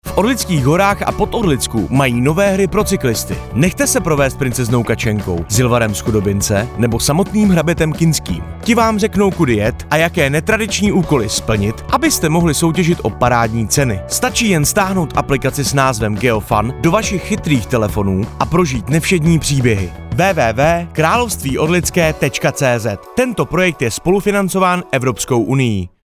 radiospot Orlické cyklo a inline království